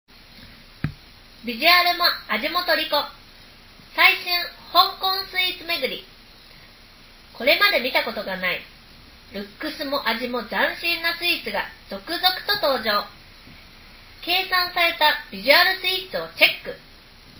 また、本製品に内蔵されたハイエンドデュアルマイク「Knowles Sisonic」による録音の質も高い。
▼PLAUD NOTE内蔵マイクによる録音データ
ノイズキャンセリングはそこまで強くないが、発言内容がクリアに録音されており、聴き取りやすい。